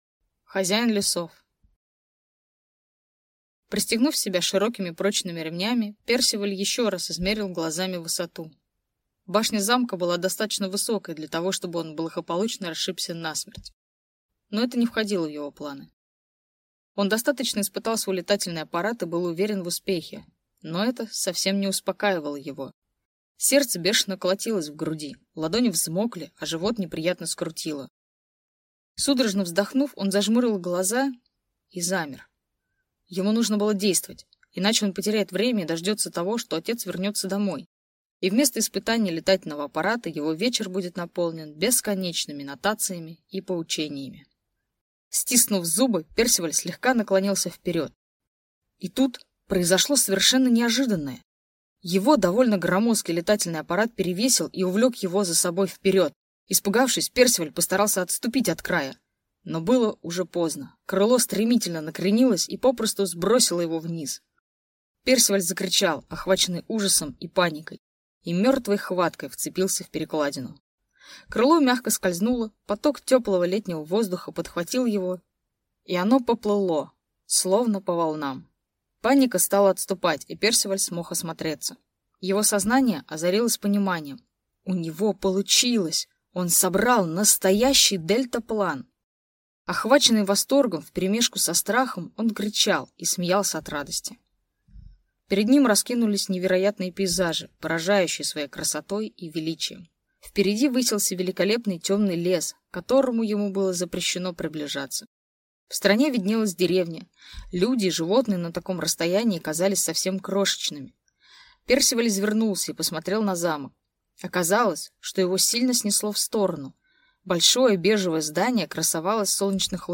Аудиокнига Хозяин лесов | Библиотека аудиокниг